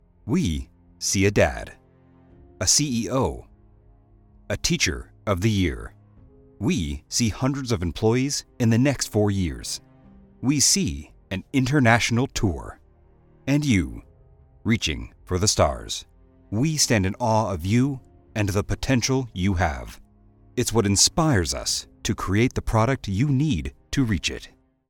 Male
I provide a warm, deep range with various styles.
Corporate
Words that describe my voice are Deep, Warm, Narrative.
All our voice actors have professional broadcast quality recording studios.